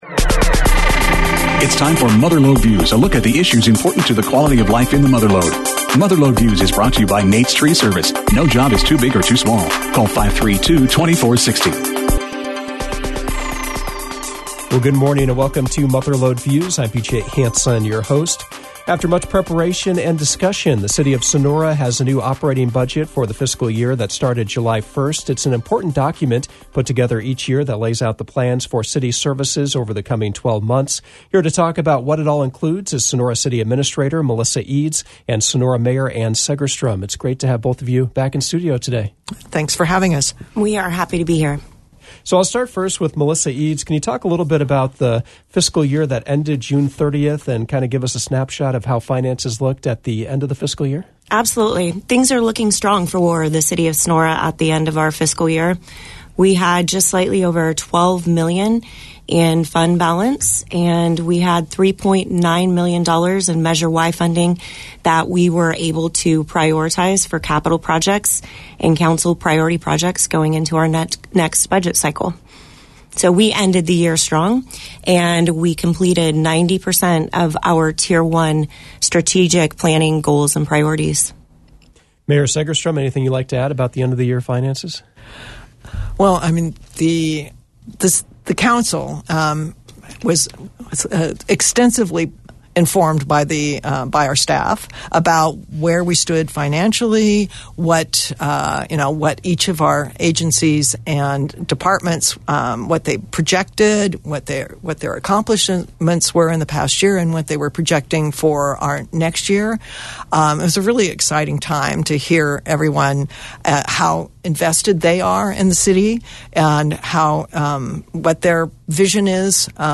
Mother Lode Views focused on the new Fiscal Year 2025-26 spending plan for the City of Sonora. There was also discussion about the need for a new police station, economic development planning, and the city’s strategic plan. The guests were Mayor Ann Segerstrom and City Administrator Melissa Eads.